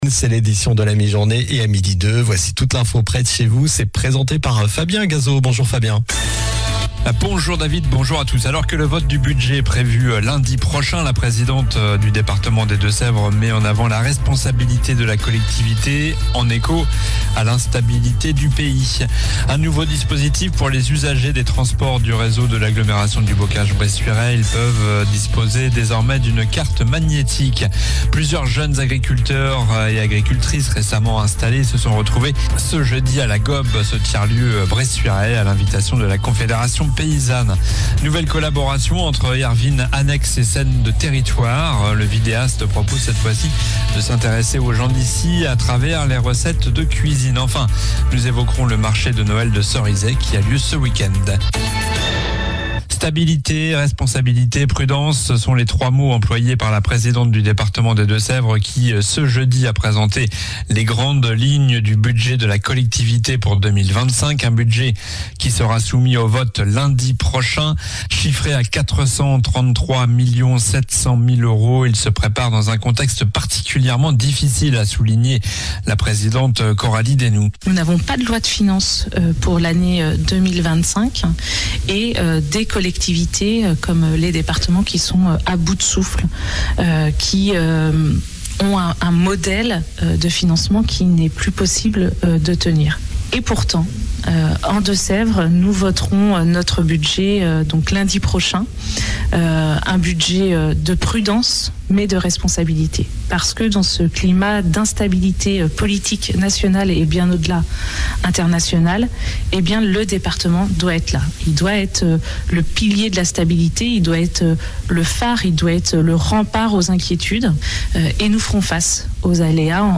Journal du vendredi 13 décembre (midi)